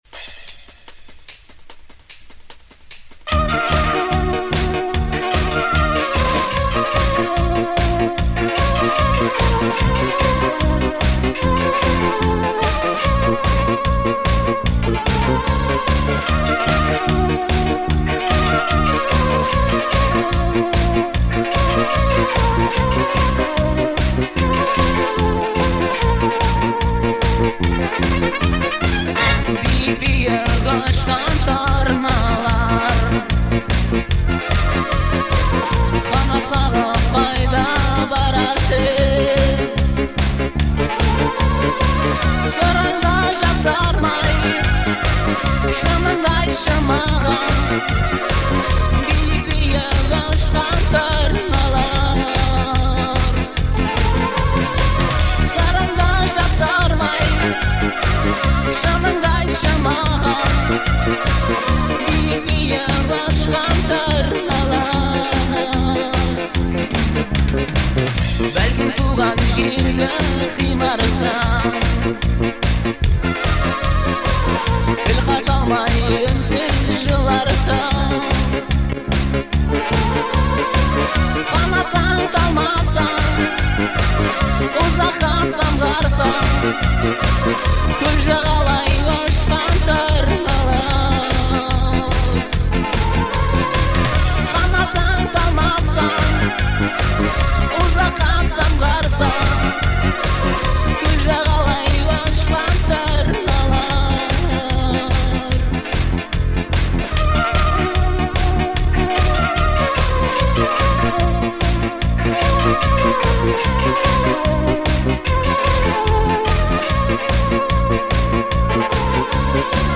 Звучание отличается мелодичностью и душевностью